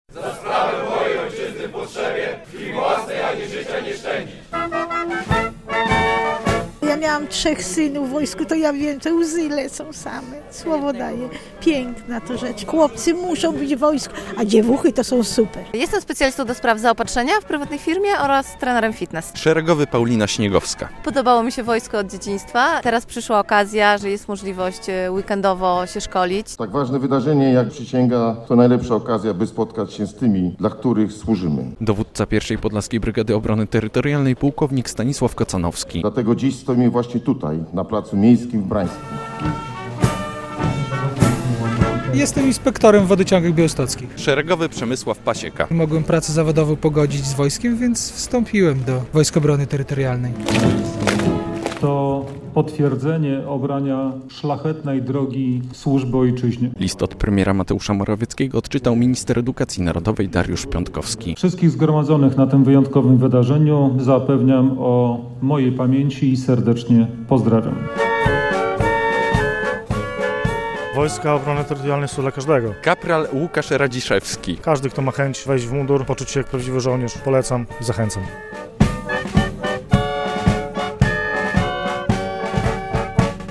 Ponad 50 żołnierzy WOT złożyło w Brańsku ślubowanie - relacja